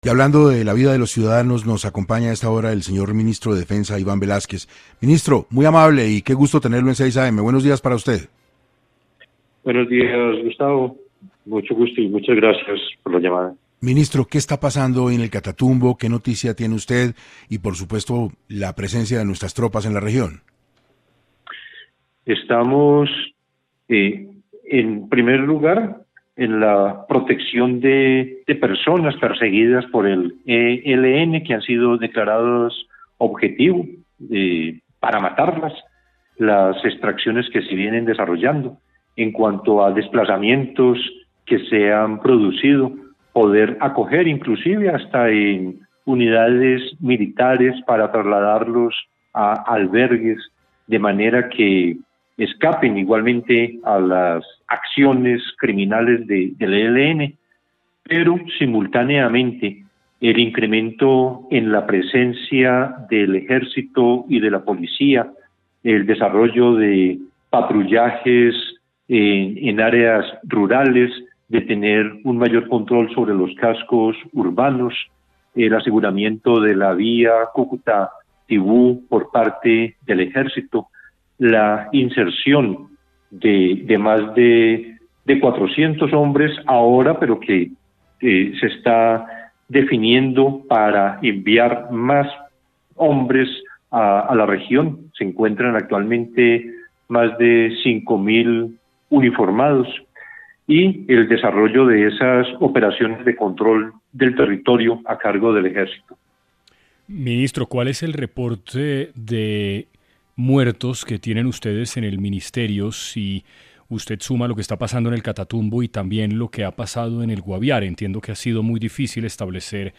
Frente a esto, en diálogo con 6AM de Caracol Radio, el ministro de Defensa Iván Velásquez, aseguró que el Gobierno Nacional se centra en el envío de ayuda humanitaria y rescate de personas en el Catatumbo, especialmente de los que son objetivo del ELN.